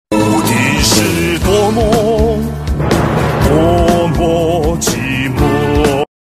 SFX音效